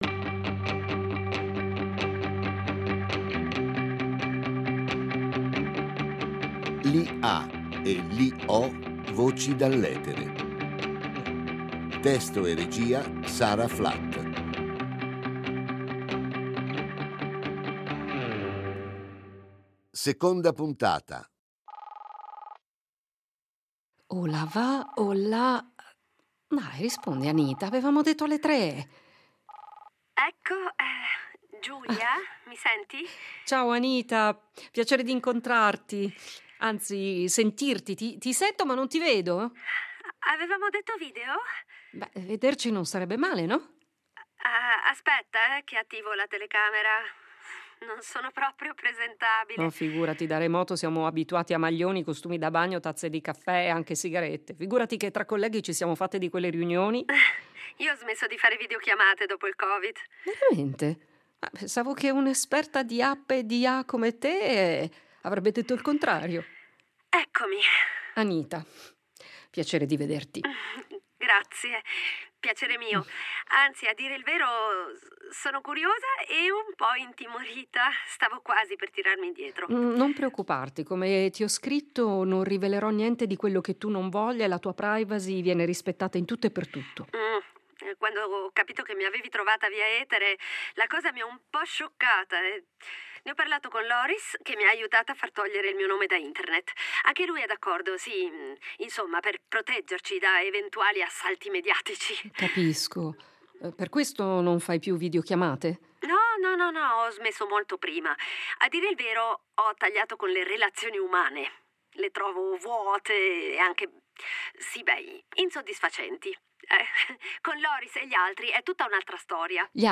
un’IA evoluta, impersonata dalla voce più che reale